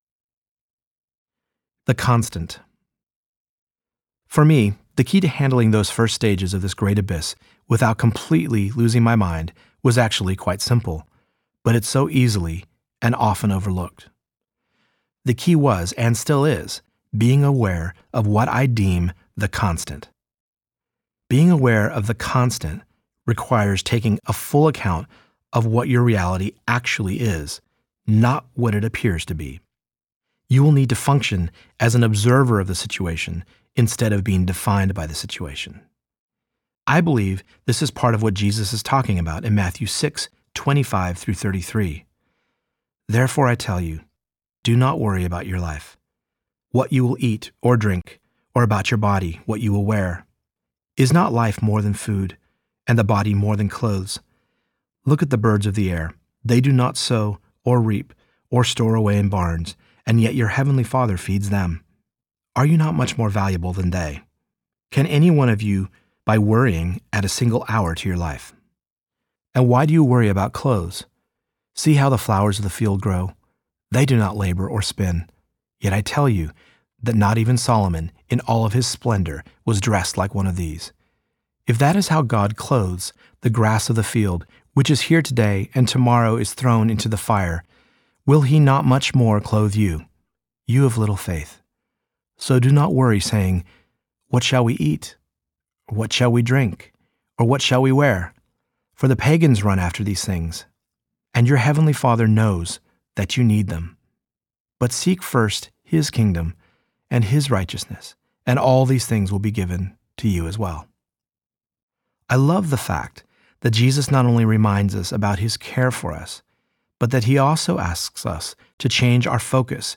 Going Solo Audiobook
Narrator
5.25 Hrs. – Unabridged